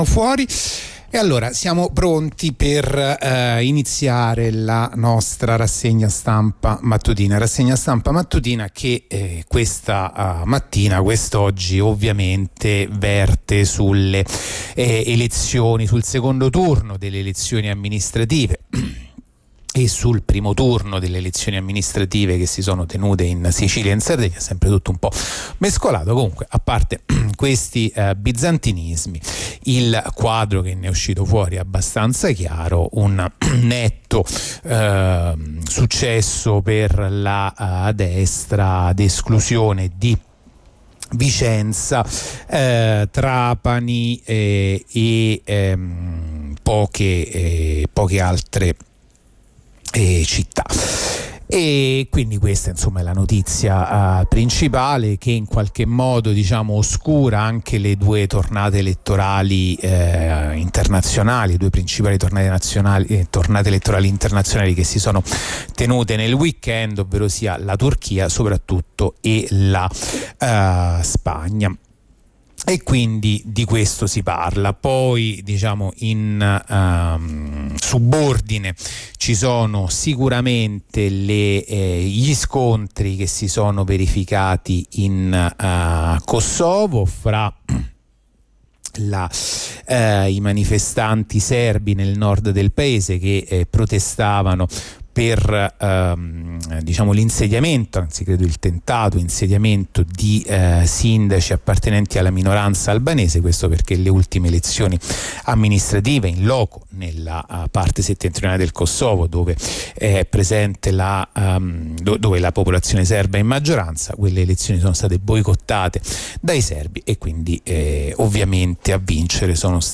La rassegna stampa di martedì 30 magio 2023